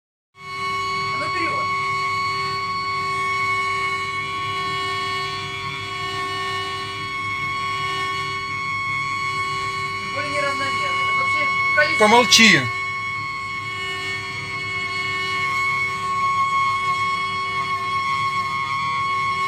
После запуска, практически сразу, котел начинает издавать скрежещуший звук, который длится до 2 минут. Потом постепенно исчезает.